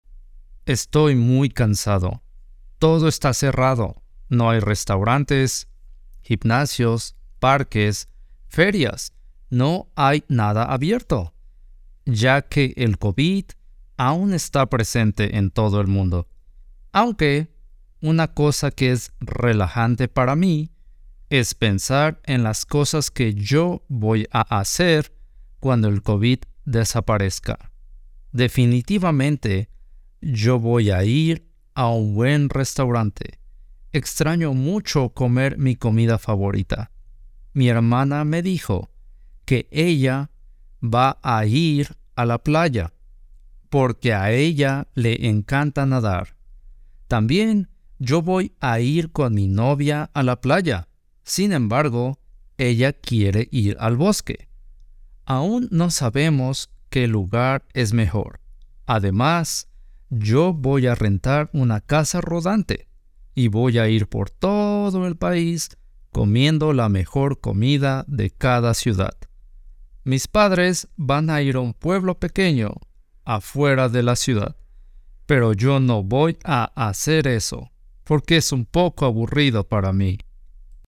Mis planes para después del Covid - Audio Story to learn Spanish